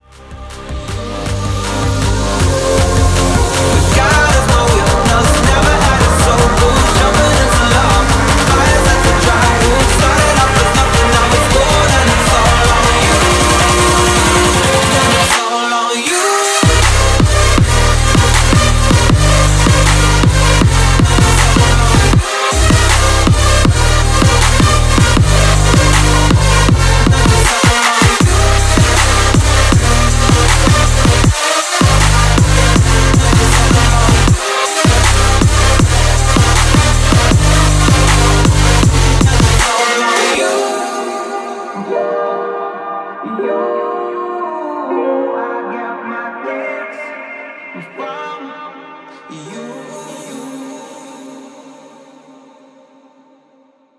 • Качество: 320, Stereo
громкие
мощные
Electronic
нарастающие
Trap